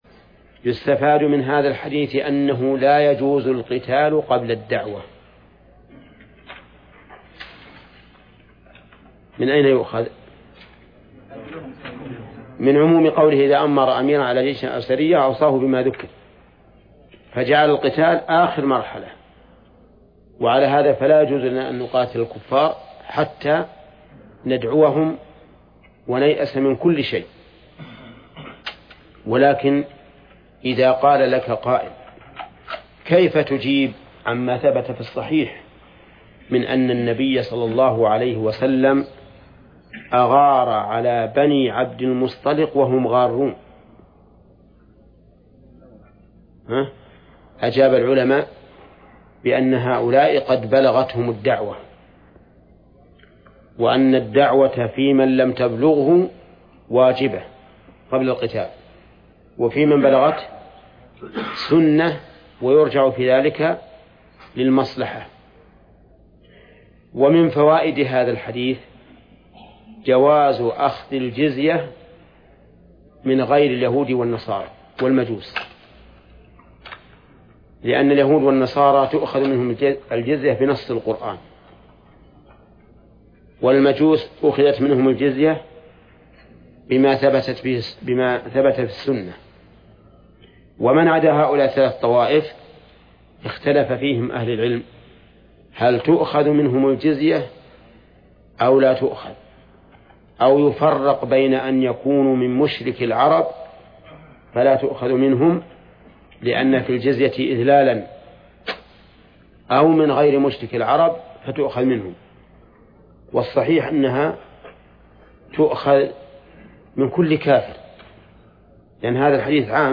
درس(53) / المجلد الثاني : من صفحة: (490)، قوله: (ويستفاد من هذا الحديث:..).، إلى صفحة: (514)، قوله: (باب ما جاء في حماية النبي حمى التوحيد ..).